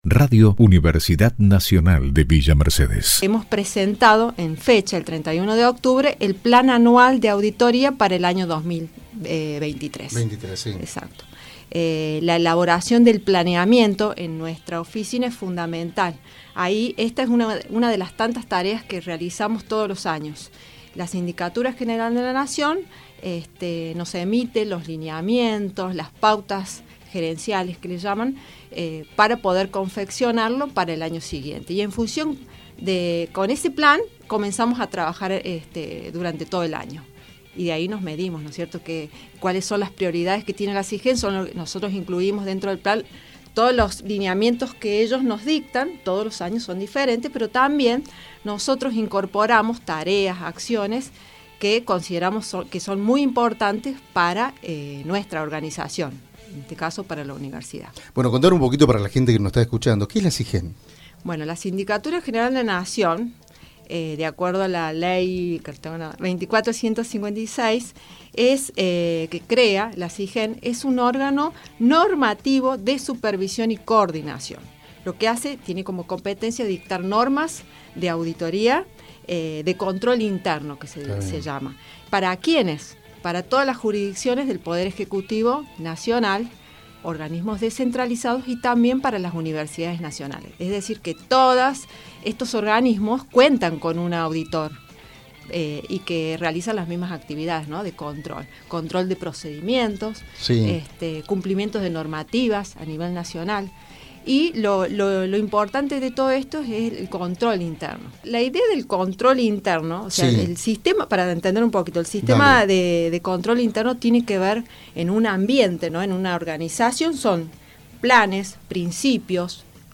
En entrevista con Radio UNViMe